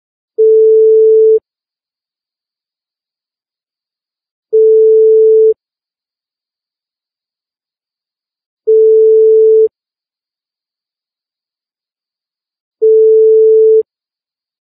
Вы можете слушать онлайн и скачать бесплатно в mp3 рингтоны входящих звонков, мелодии смс-уведомлений, системные звуки и другие аудиофайлы.